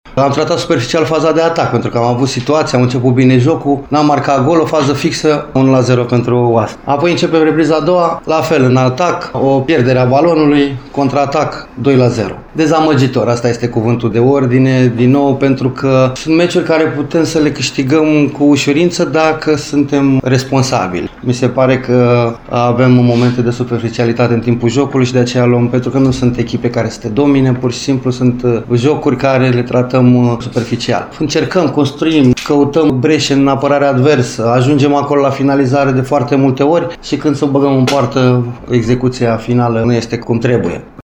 La final, antrenorul cărășenilor, Flavius Stoican, a vorbit despre superficialitate pe faza de atac din partea echipei sale: